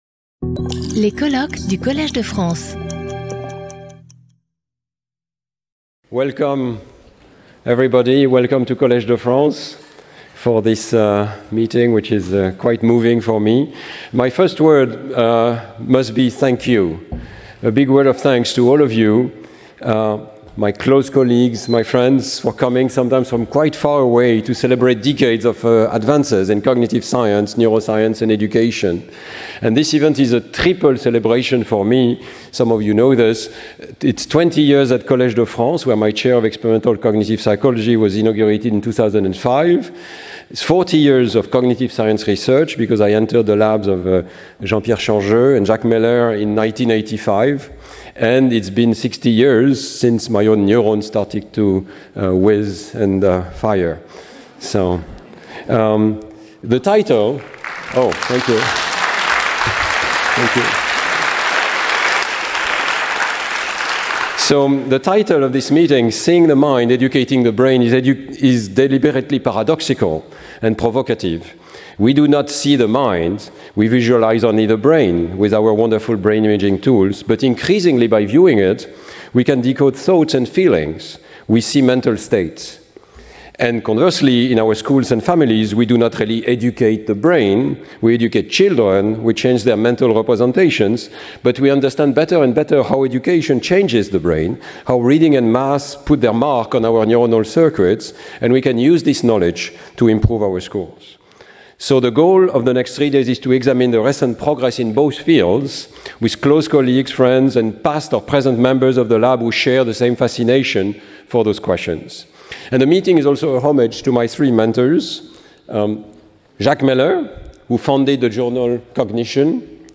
Intervenant(s) Stanislas Dehaene Professeur du Collège de France
Colloque 01 Oct 2025 09:00 à 09:10 Stanislas Dehaene Introduction